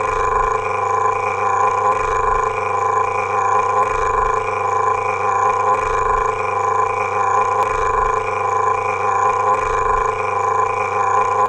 地膜鼓风机
描述：我在街上路过的一台吹地膜机。 未经处理的。 里面有有趣的谐波。
标签： 雄蜂 发动机 设备 机器 电动机
声道立体声